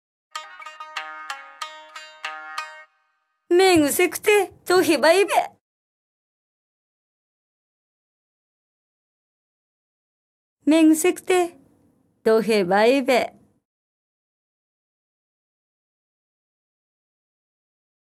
本場のお国言葉を読み上げる方言かるたシリーズ第二弾「津軽弁かるた」が新登場！